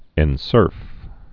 (ĕn-sûrf)